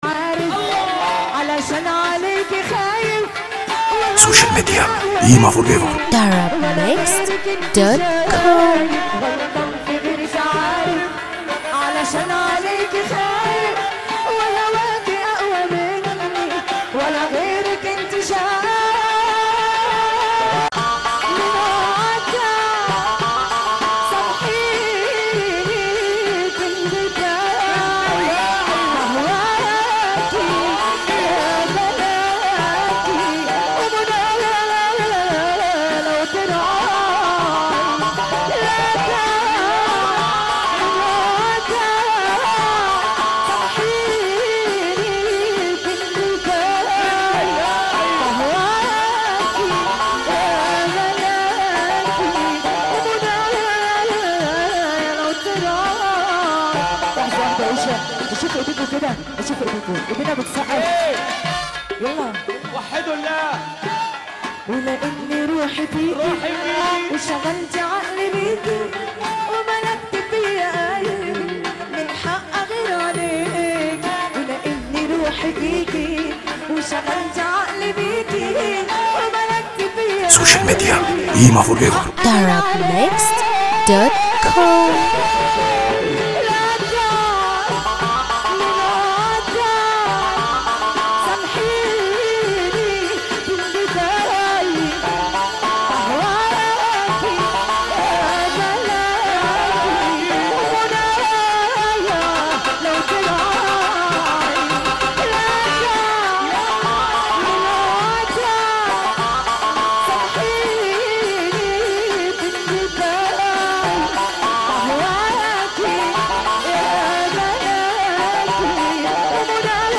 موال
باقوى احساس